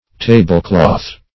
Definition of tablecloth.
Meaning of tablecloth. tablecloth synonyms, pronunciation, spelling and more from Free Dictionary.